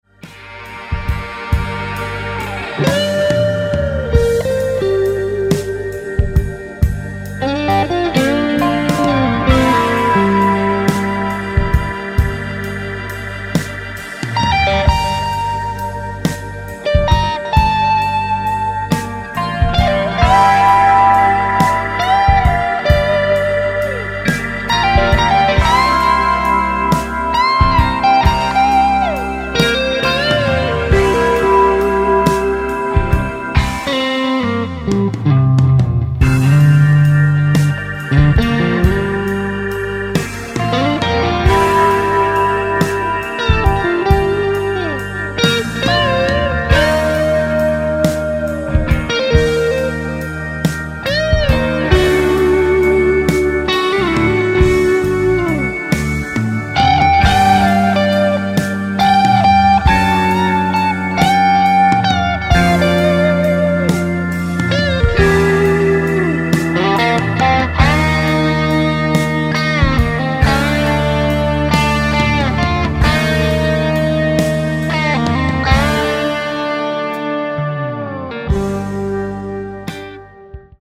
Black Strat > WEMBLEY > Delay > Amp